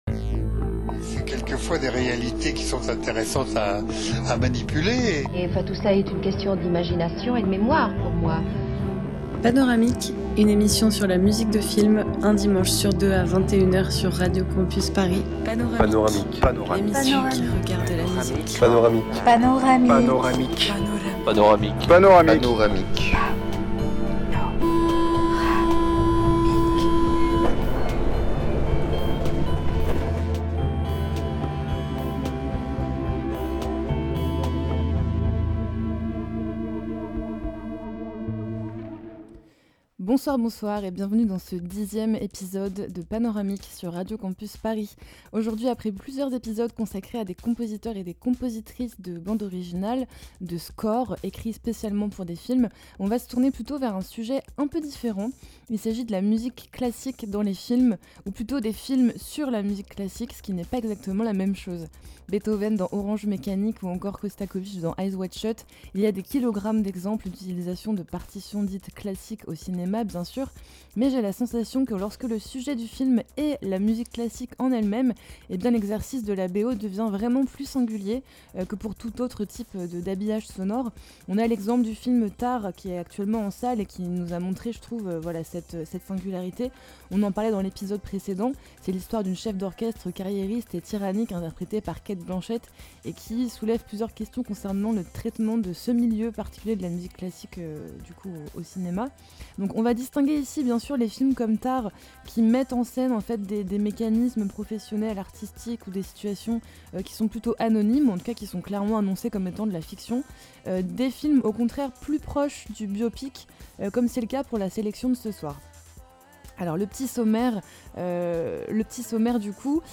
Type Mix
Éclectique